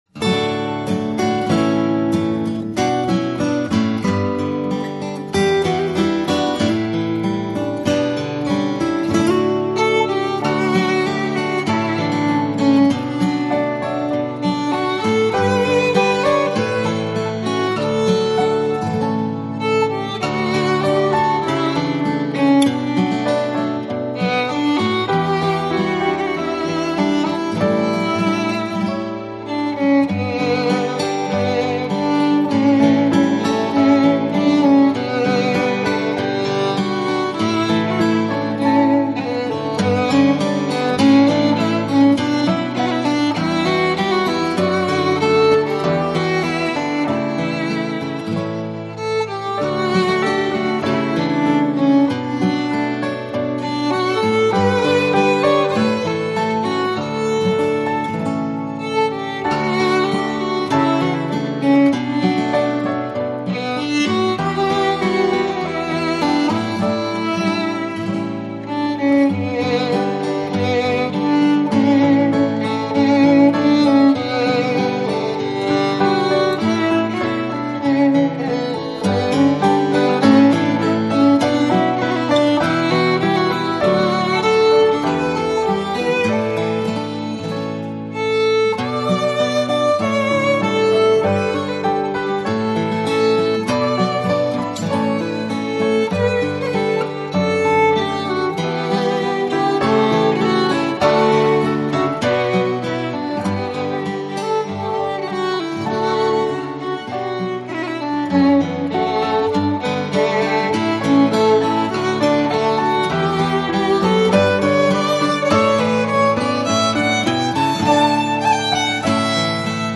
9楼更新一首（大爱的小提琴曲+钢琴）
爆好听的小提琴加钢琴曲